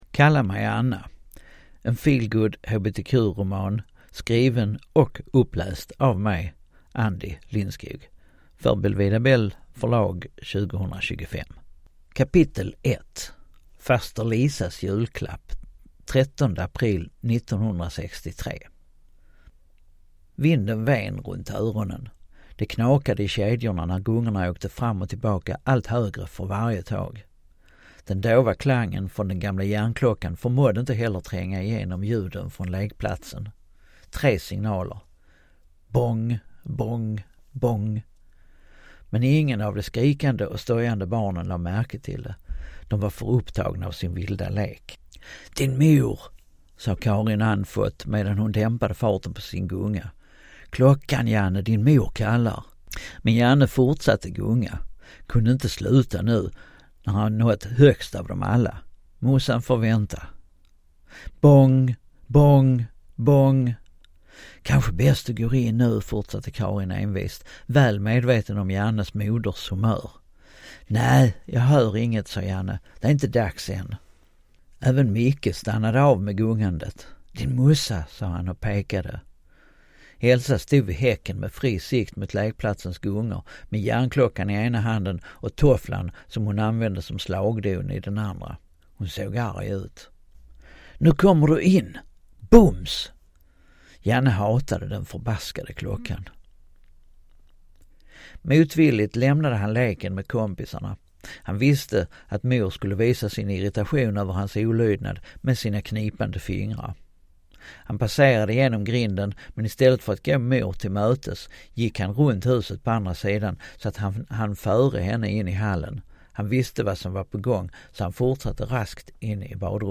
Kalla mig Anna (ljudbok) av Andie Lindskog